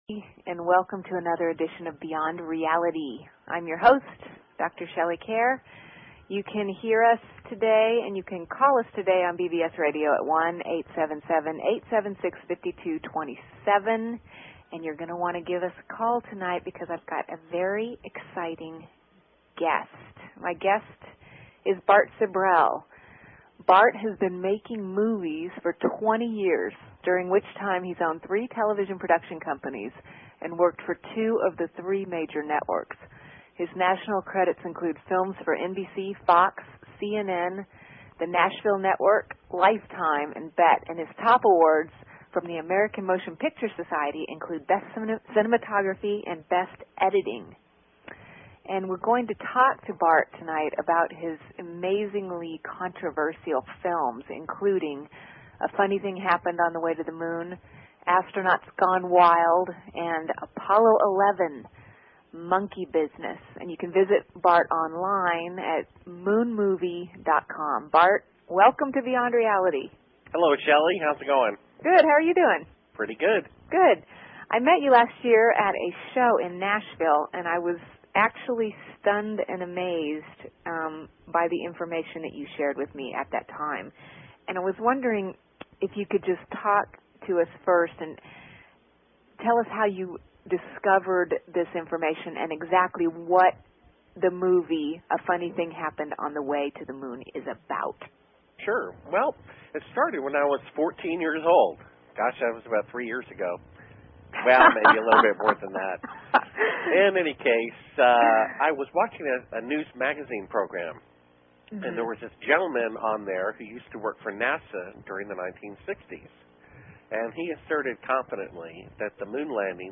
Talk Show Episode, Audio Podcast, Beyond_Reality and Courtesy of BBS Radio on , show guests , about , categorized as